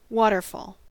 waterfall-us.mp3